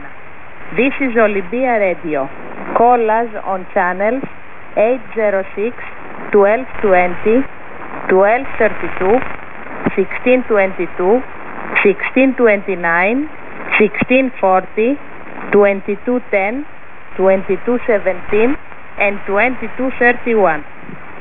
Emetteur HF de Athinai (Grece) indicatif SVN
Athenes_Radio.wav